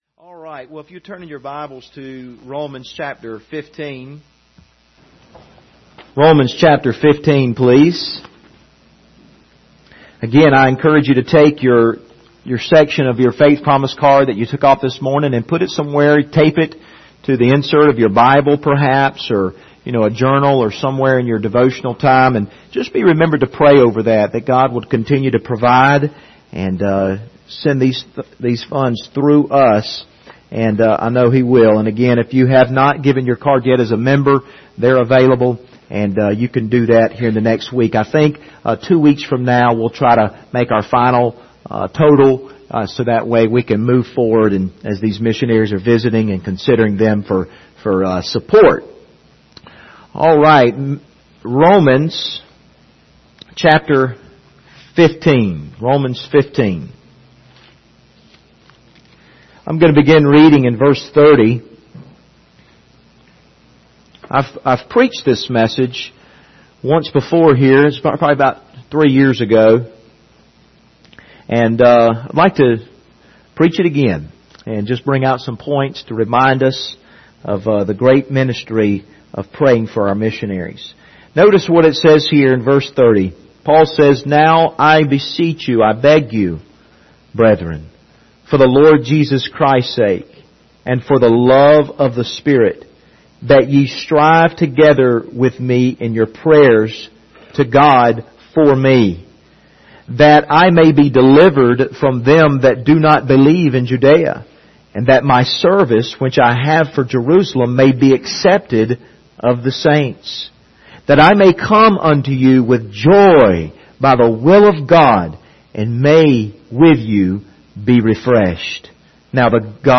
Passage: Romans 15:30-32 Service Type: Sunday Evening Topics